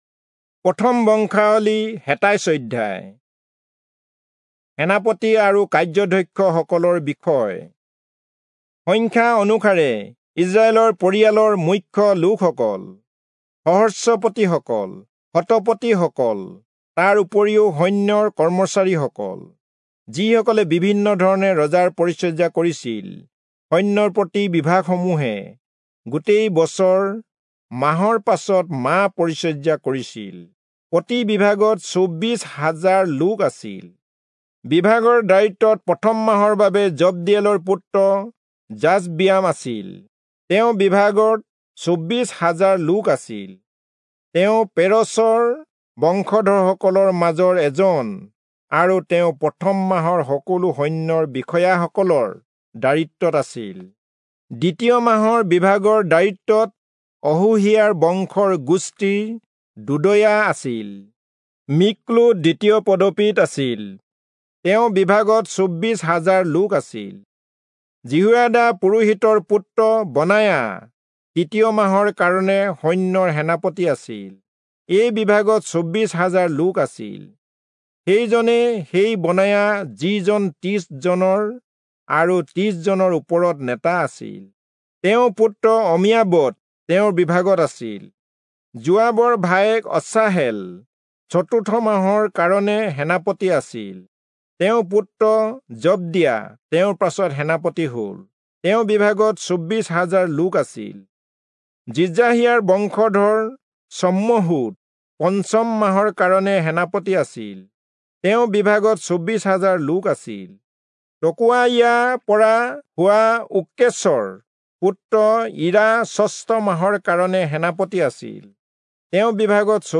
Assamese Audio Bible - 1-Chronicles 11 in Ervml bible version